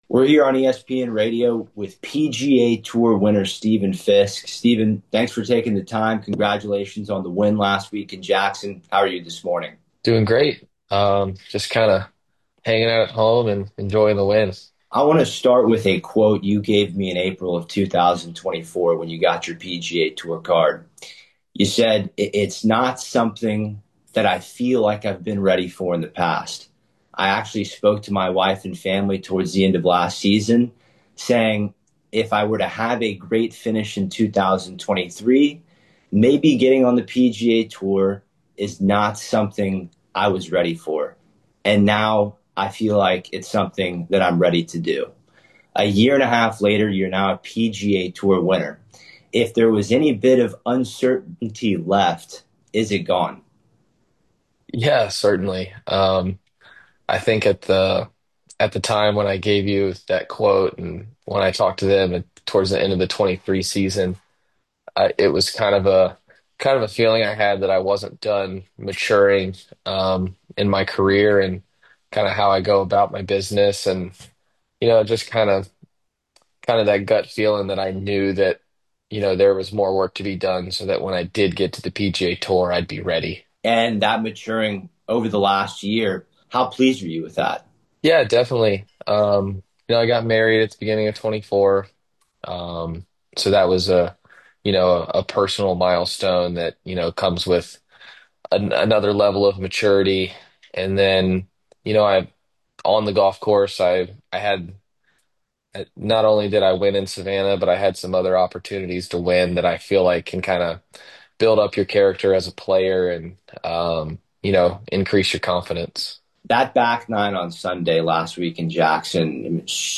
an intimate and thoughtful conversation about composure, connection, and the people who shaped his journey.